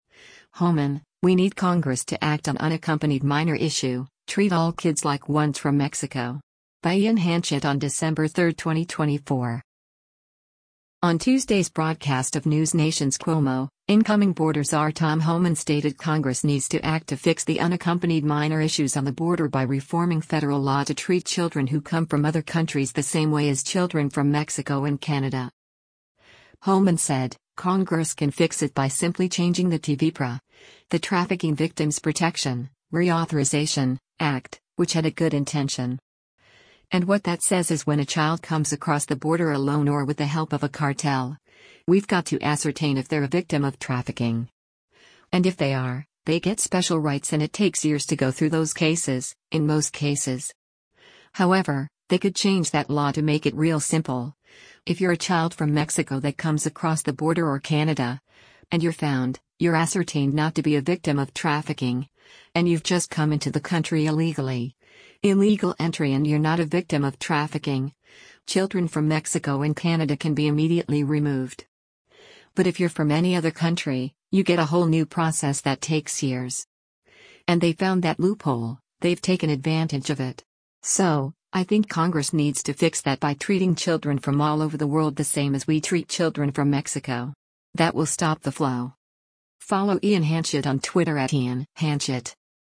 On Tuesday’s broadcast of NewsNation’s “Cuomo,” incoming Border Czar Tom Homan stated Congress needs to act to fix the unaccompanied minor issues on the border by reforming federal law to treat children who come from other countries the same way as children from Mexico and Canada.